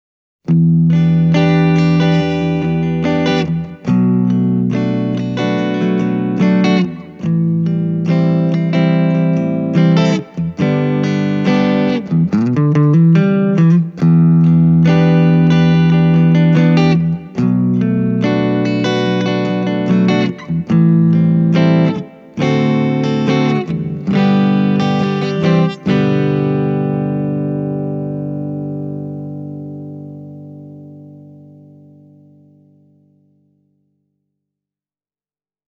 Myös humbuckereilla täytyy säätää kitaran omat volumet alas, muuten Vox AC10:n soundi muuttuu jo hieman rosoiseksi. Tässä kaksi esimerkkiä Hamer USA Studio Custom -kitaralla: